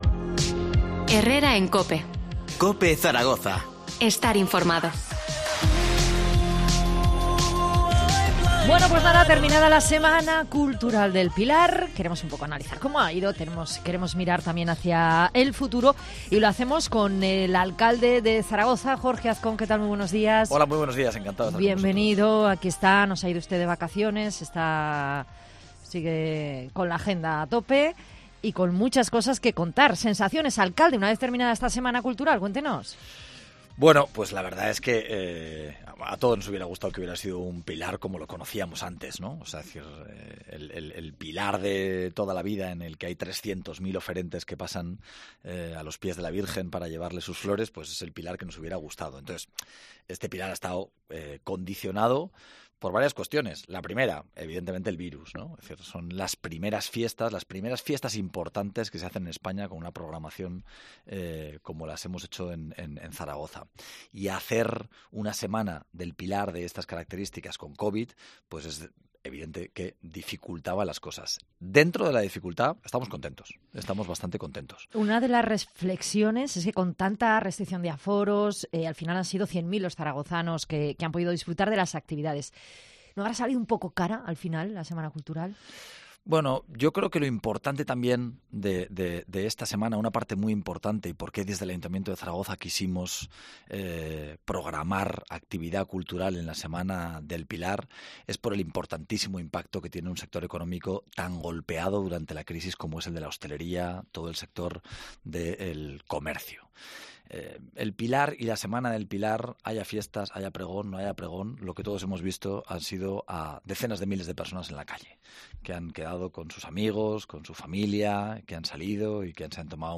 Entrevista a Jorge Azcón, alcalde de Zaragoza, terminada la Semana del Pilar